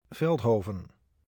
Veldhoven (Dutch pronunciation: [ˈvɛltˌɦoːvə(n)]